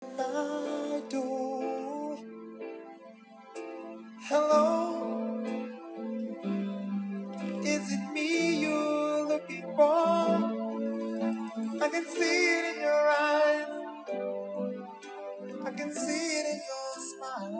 Dit is niet een telefoon gesprek, maar als ik microfoon even bij een luidspreker houd en dat opneem. Er zit ook een noise cancellation mic op. Voor wat betreft de 'ear-speaker', hij gaat in ieder geval super luid je hebt niet eens een handsfree stand nodig.